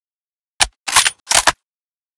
misfire.ogg